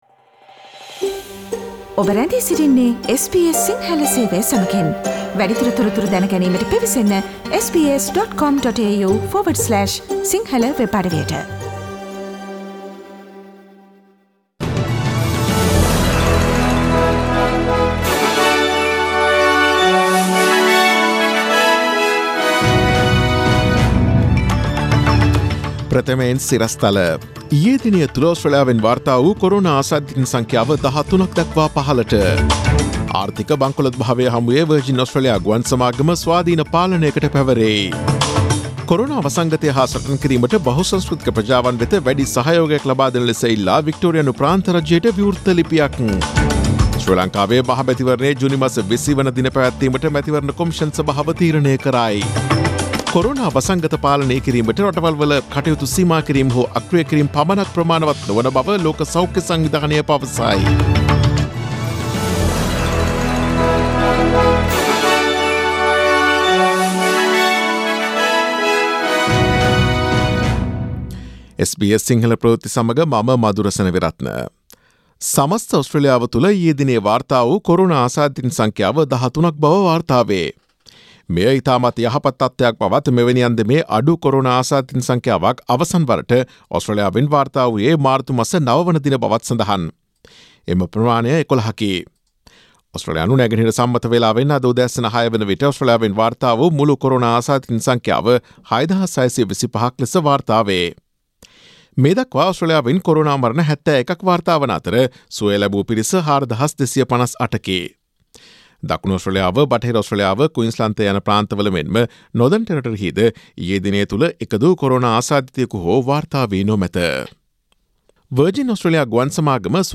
Daily News bulletin of SBS Sinhala Service: Tuesday 21 April 2020